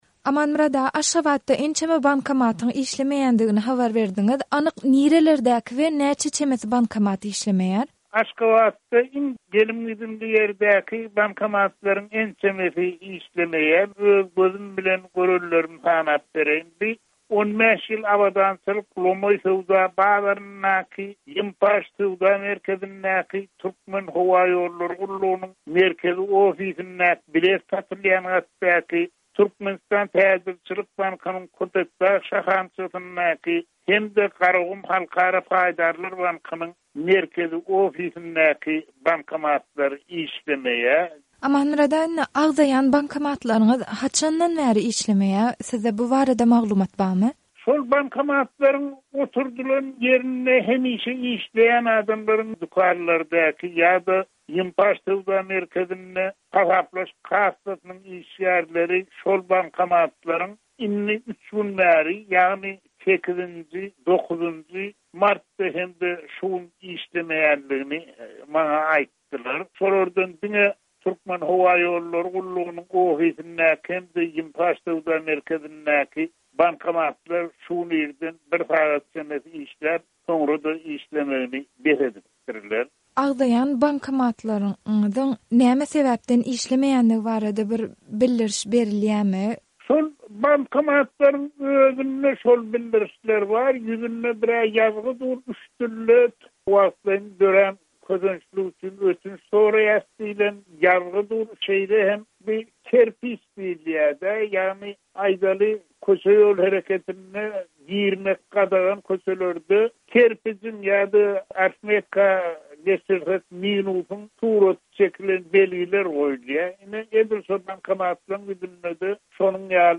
Azatlyk Radiosy bu ýagdaý we onuň bolaýmagy mümkin sebäpleri bilen gyzyklanyp, Aşgabatda ýaşaýan ýazyjy